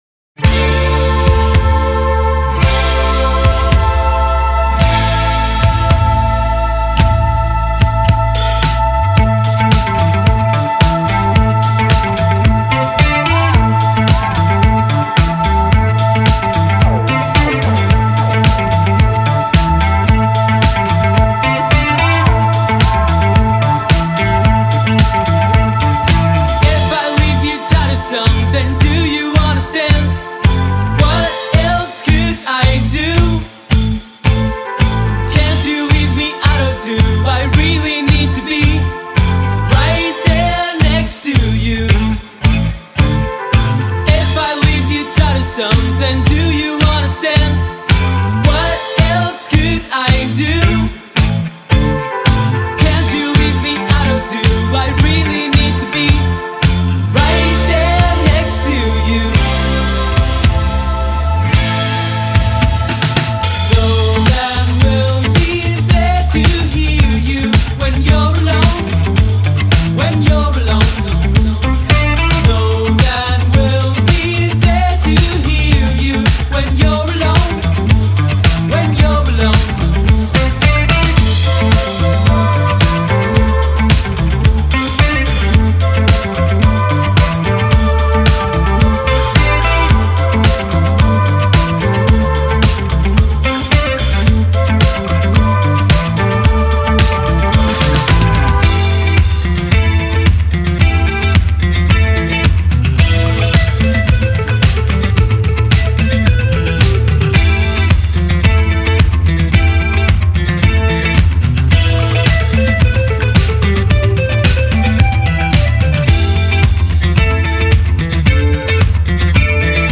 an indie rock, chill wave dance band
combine electro dance elements, chill wave, and indie rock